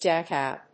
/ˈdækaʊ(米国英語)/